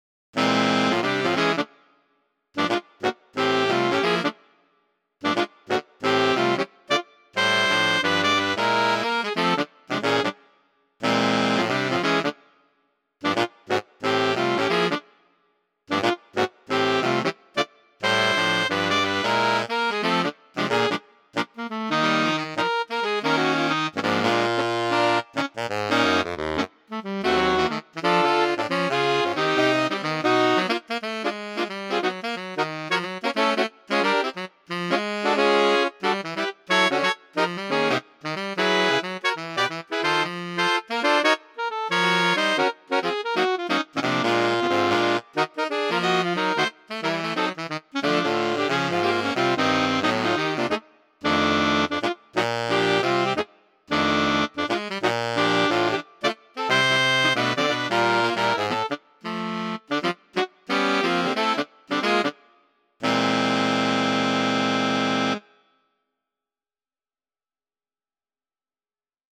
• Short Pieces for Saxophone Quartet
We managed, but the effect was very different from the precise computer-rendered versions here.